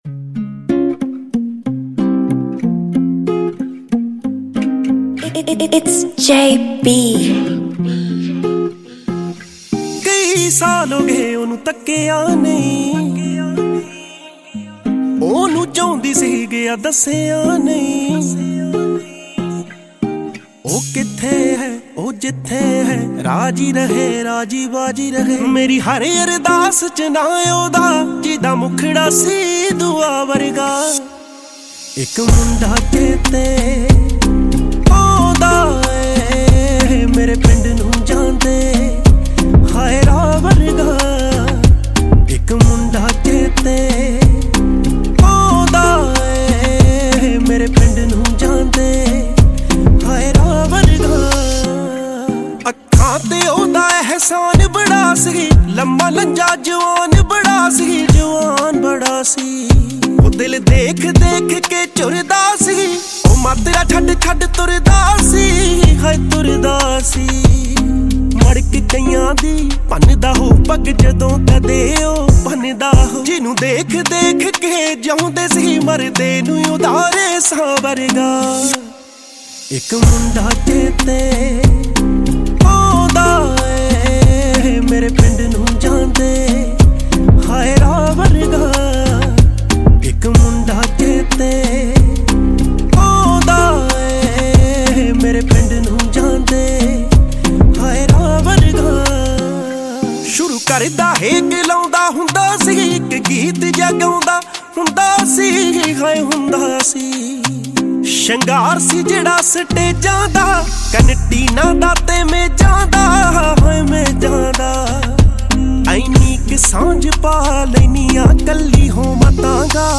sad song